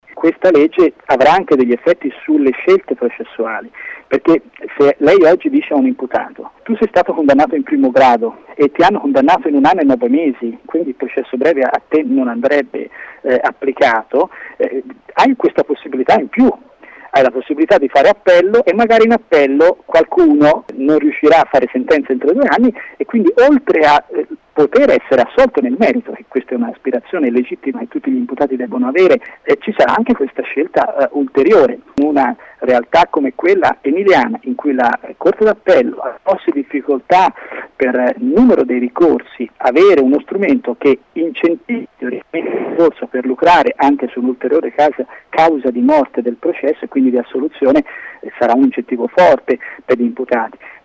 25 nov. – Il 50% dei processi d’appello in Emilia-Romagna potrebbero non concludersi se dovesse passare la legge sul processo breve. All’indomani dell’audizione in Consiglio superiore della Magistratura, a cui sono stati invitati anche i magistrati bolognesi, a parlare ai nostri microfoni è Lorenzo Gestri, magistrato e presidente dell’Anm regionale.